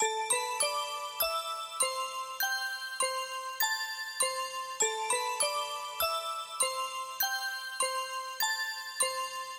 描述：阿拉伯沙漠的噪音
Tag: 120 bpm Hip Hop Loops Flute Loops 1.35 MB wav Key : C